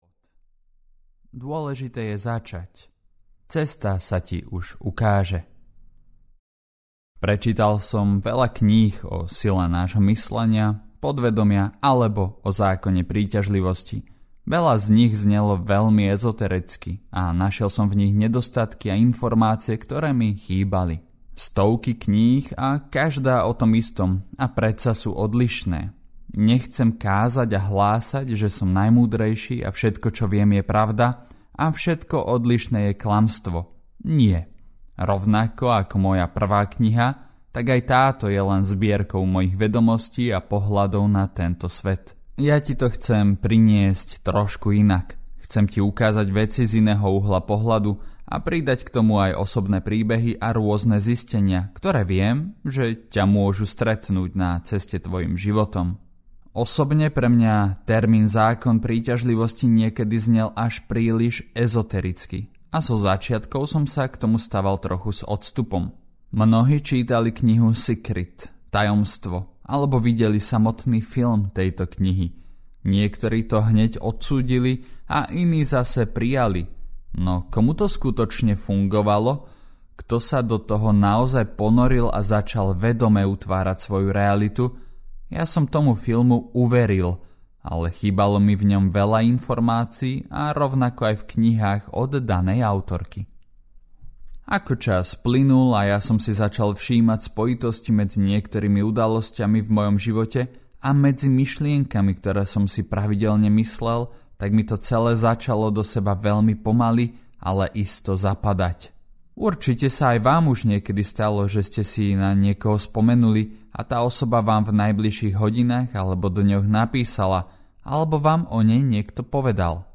Umenie Života audiokniha
Ukázka z knihy